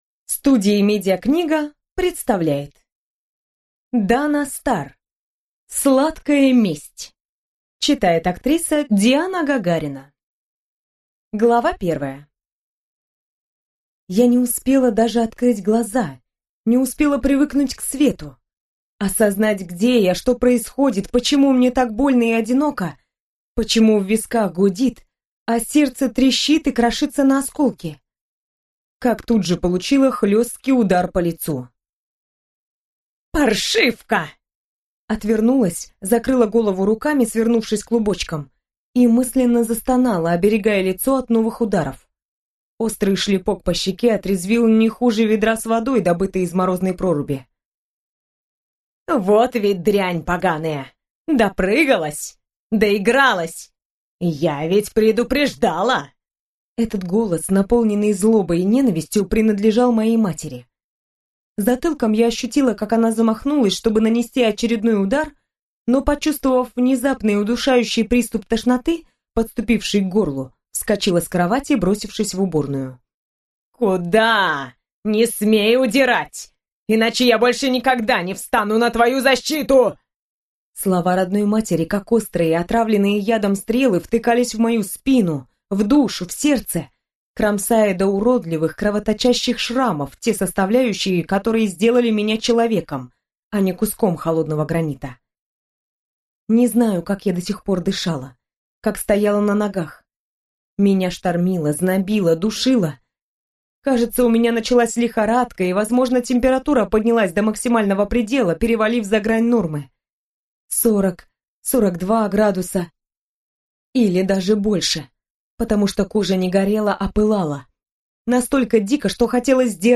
Аудиокнига Сладкая месть | Библиотека аудиокниг
Прослушать и бесплатно скачать фрагмент аудиокниги